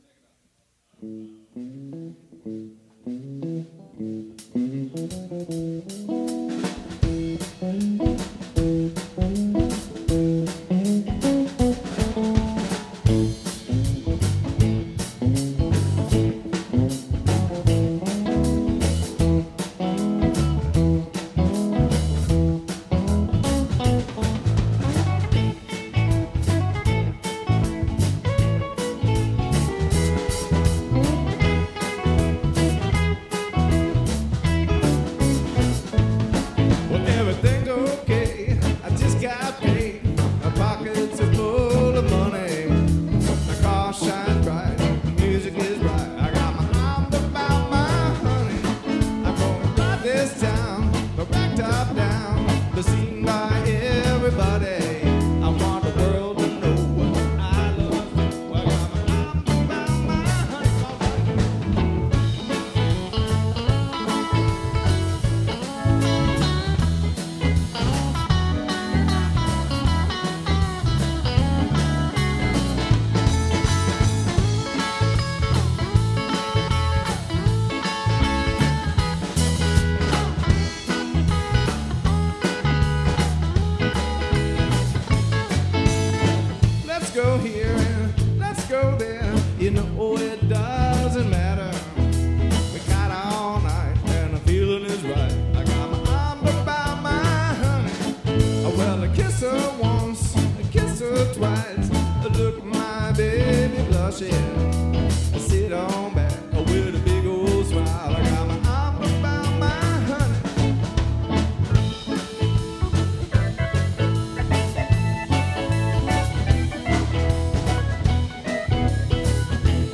lead vocals, percussion, guitar
keyboards, vocals
saxophone
bass guitar
drums